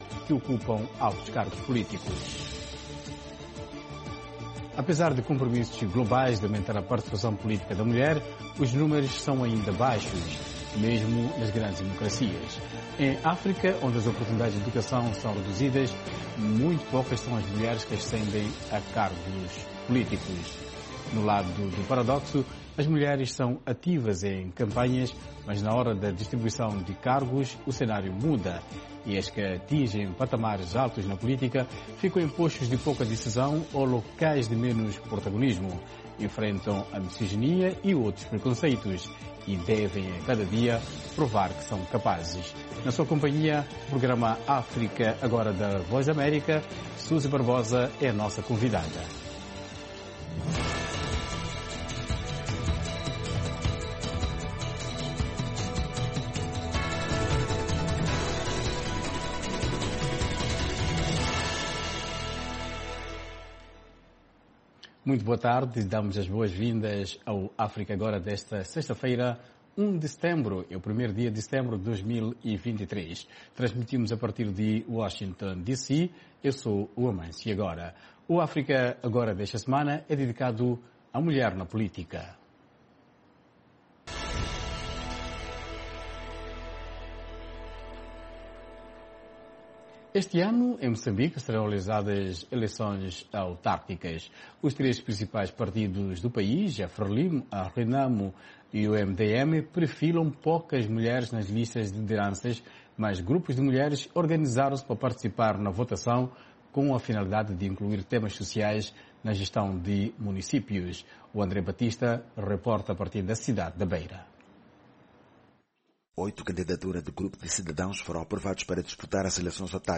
Um debate sobre temas actuais da África Lusófona.